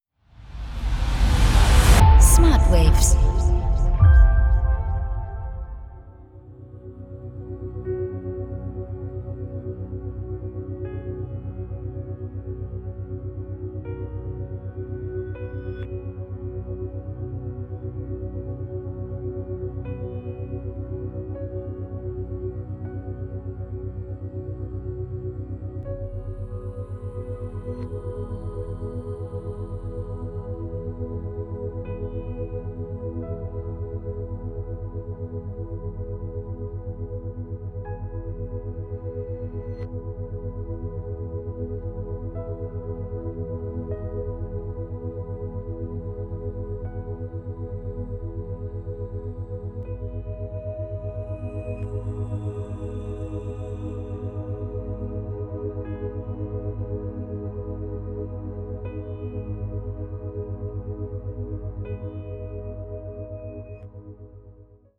Isochrone Beats